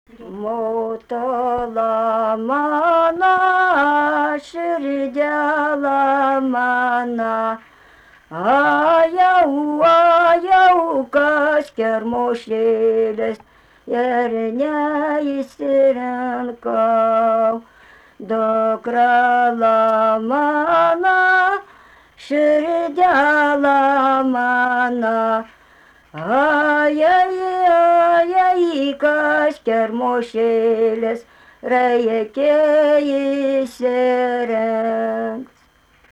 vokalinis
2 balsai